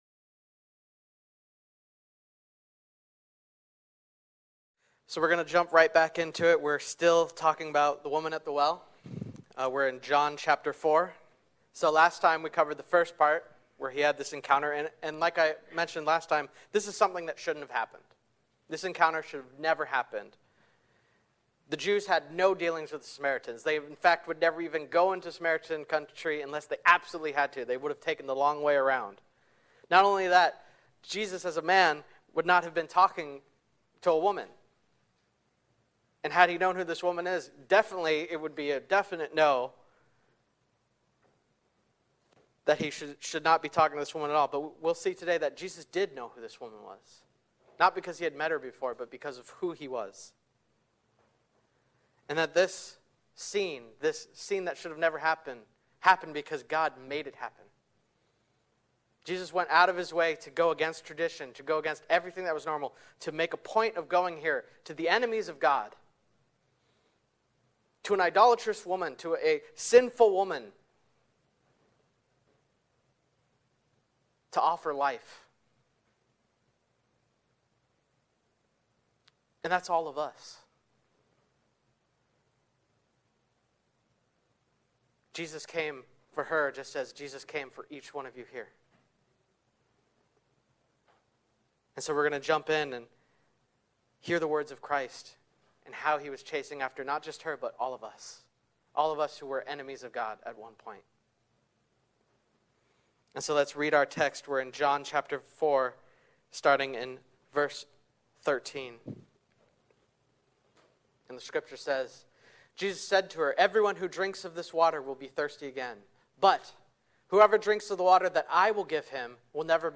English Service 2024:Mitaki Green Chapel, Hiroshima, Church